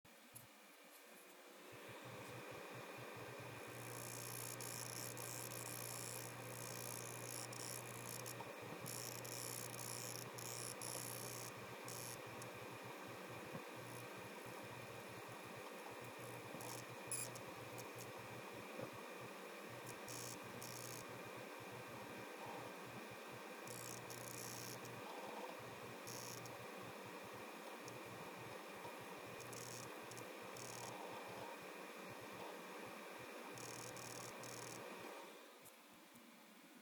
(!) BUT this is where I hope to get some assitance from you experts here: The Card makes a kind of static noise (knick knick knick knick) - Whenever there is something to draw on the screen.
It sounds like a contamination meter - and it starts after the card heaten up.
I just captures an audiofile with my iPhone:
You can here the clickering noise... this is while I draw an selectrectangle over the desktop. The fun thing is the pitch of the noise alternates, depends on how big the rectangle is.
In the last third of the file you can listen to the sound which happens everytime i scroll through a Webpage...
noise.m4a